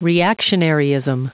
Prononciation du mot reactionaryism en anglais (fichier audio)
Prononciation du mot : reactionaryism